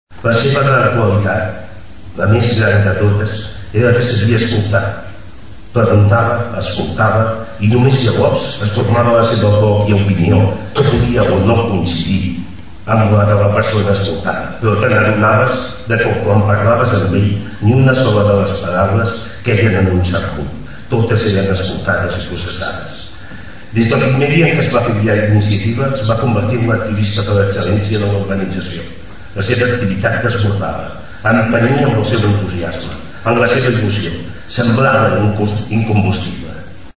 Va ser un acte senzill, però emotiu: diversos parlaments i algunes projeccions amb imatges de la seva vida política, acompanyades de música en directe, van servir per recordar-lo.